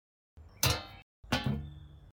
Звук открытия и закрытия крышки